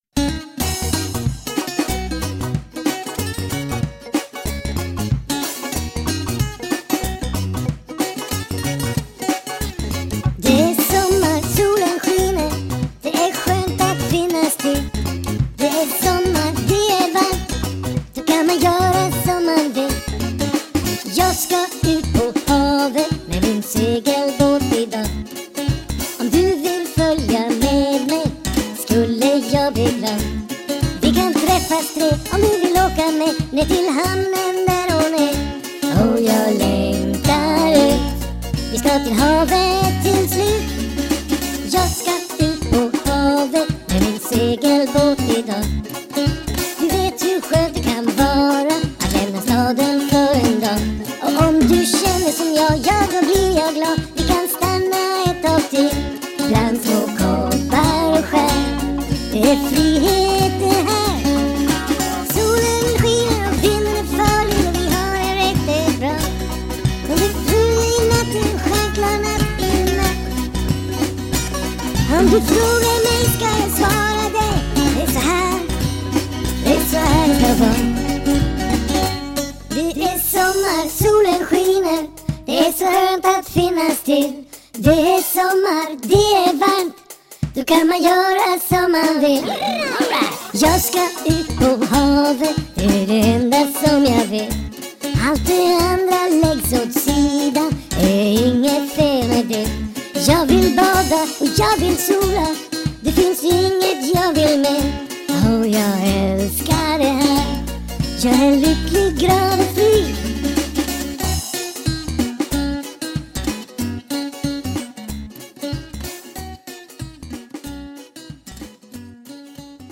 Later on they bought a Tascam Porta Studio taperecorder.